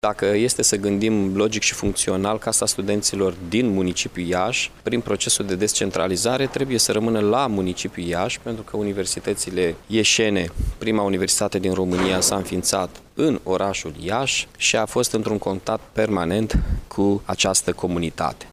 Şi Primarul Iaşului, Mihai Chirica, s-a pronunţat cu privire la subiectul funcţionării Casei de Cultură a Studenţilor.